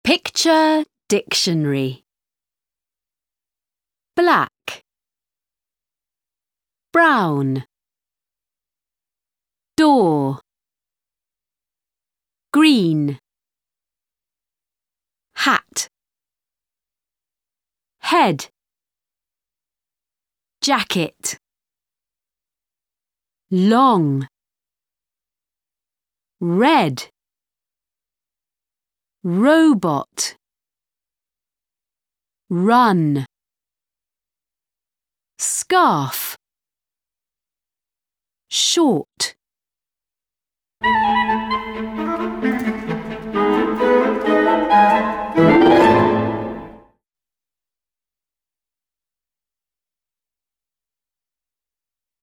Track 6 Where's My Hat British English.mp3